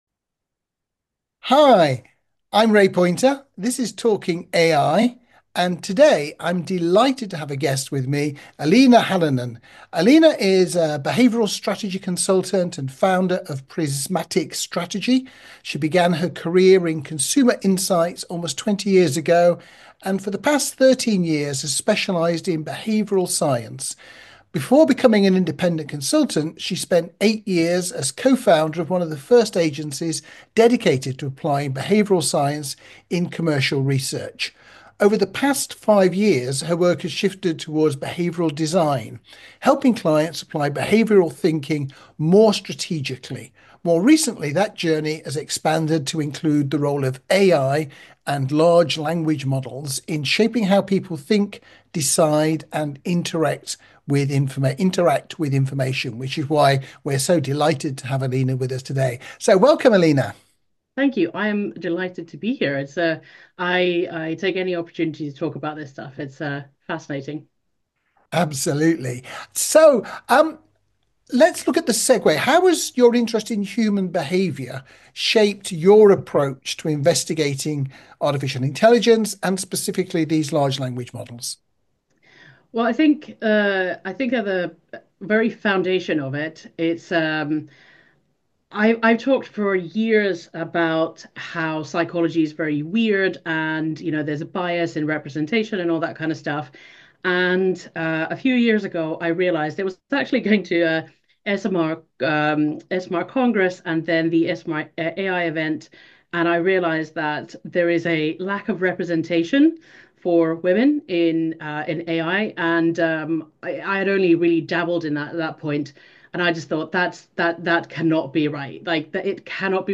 Join us for a thought-provoking discussion that bridges behavioral science with cutting-edge AI trends.